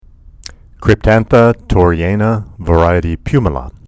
Pronunciation/Pronunciación:
Cryp-tán-tha  tor-re-yà-na var. pù-mi-la